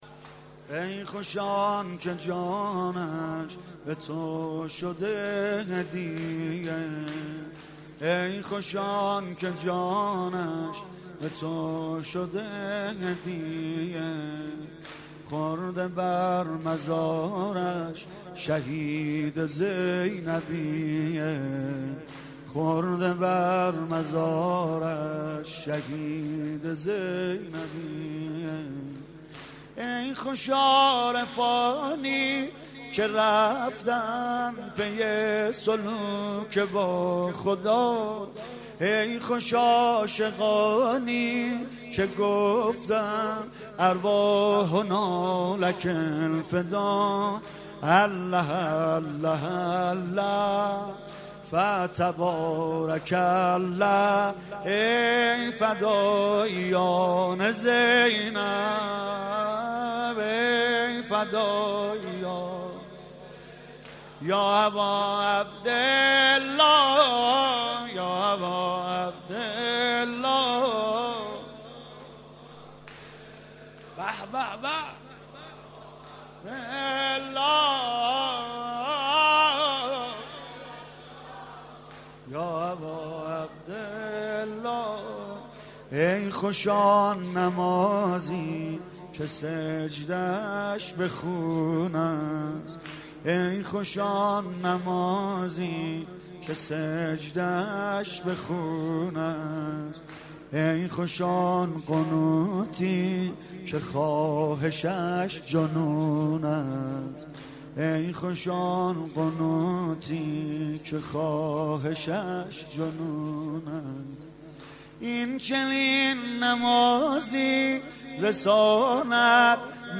مداحی و نوحه